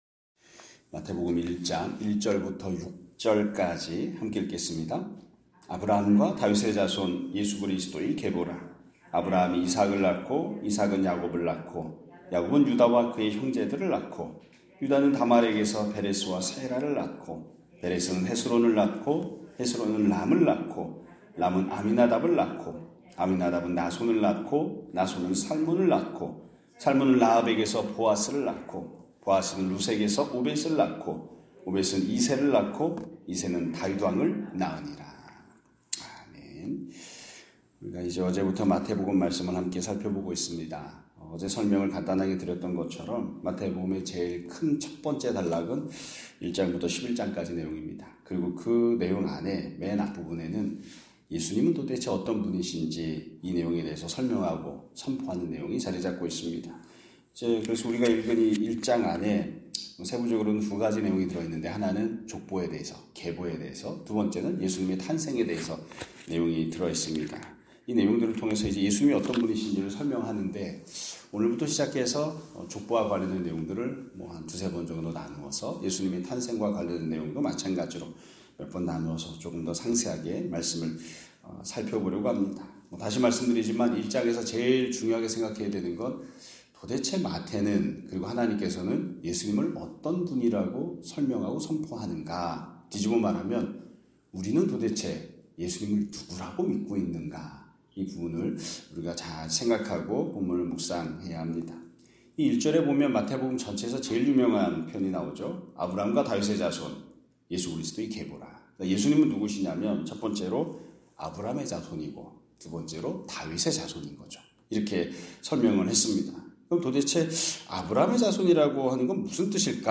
2025년 3월 19일(수요일) <아침예배> 설교입니다.